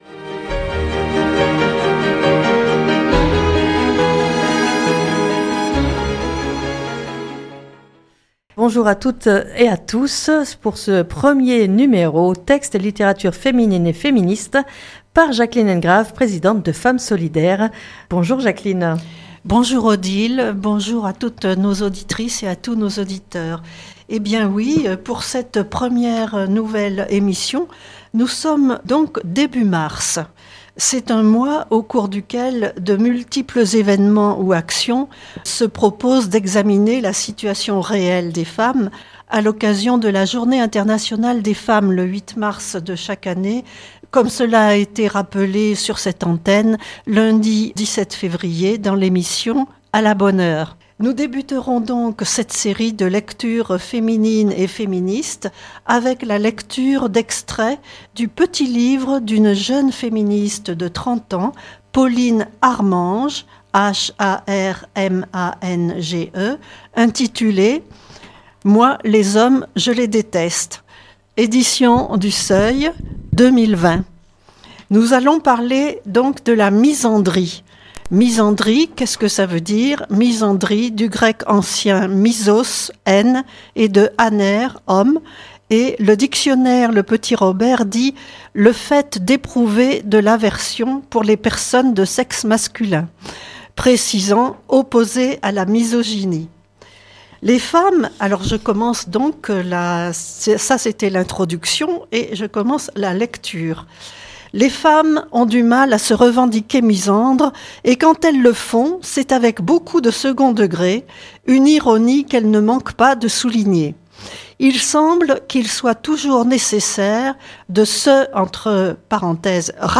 Lecture de textes et littérature féminine et féministe